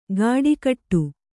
♪ gāḍi kaṭṭu